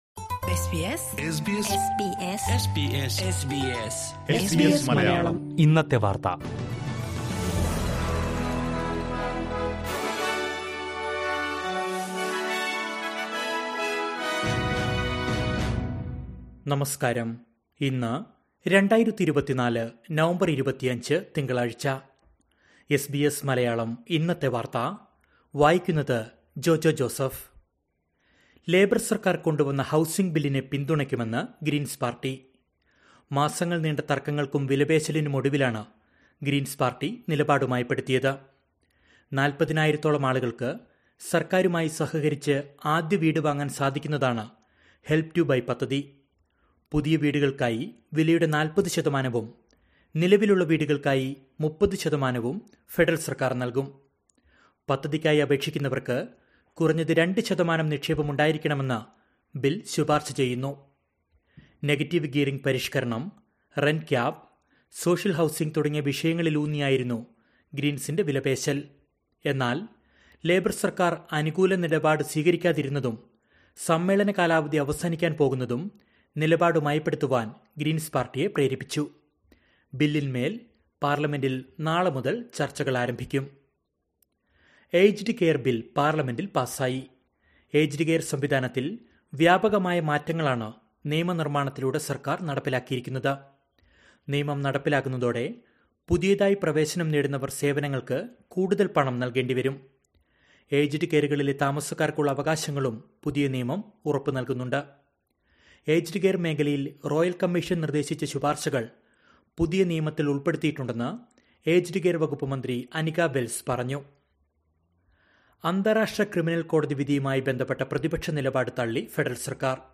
2024 നവംബർ 25ലെ ഓസ്ട്രേലിയയിലെ ഏറ്റവും പ്രധാന വാർത്തകൾ കേൾക്കാം...